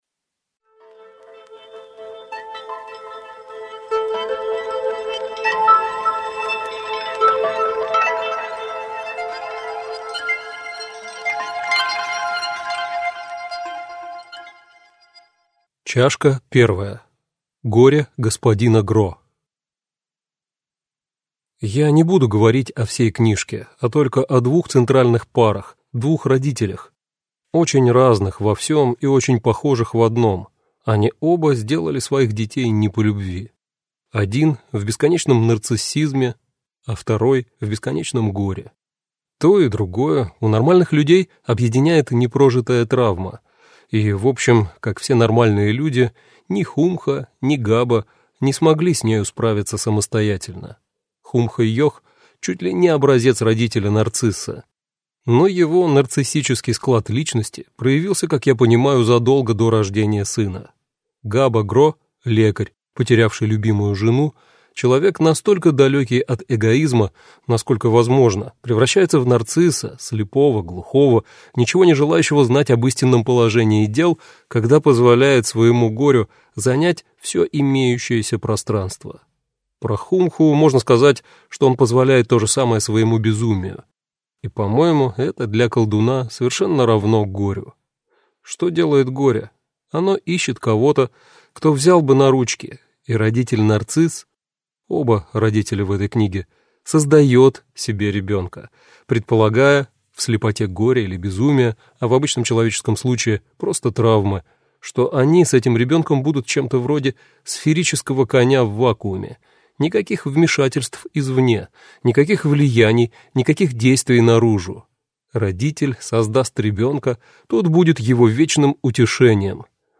Аудиокнига Все о мире Ехо и немного больше.